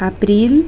April